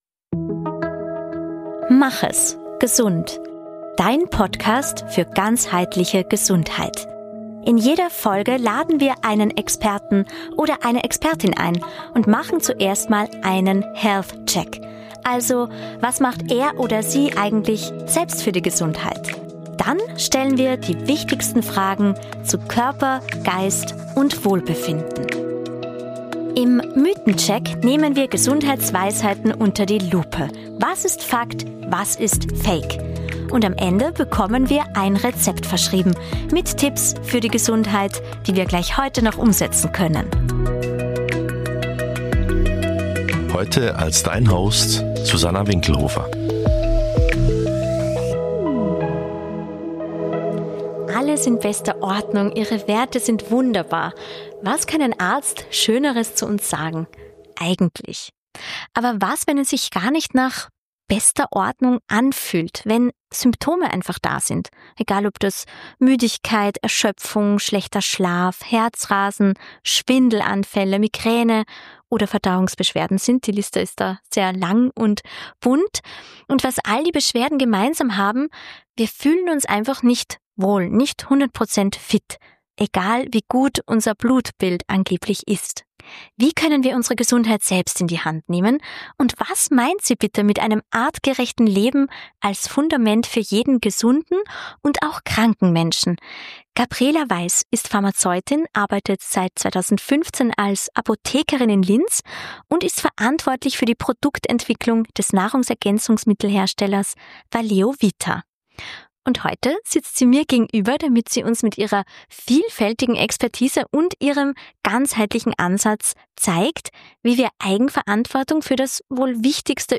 Ein Gespräch über stille Warnzeichen, über Müdigkeit, die keine Pause kennt, über Omega 3, Vitamin D und Magnesium – aber auch über Kuchen, Kinderlachen und das unersetzbare Bauchgefühl.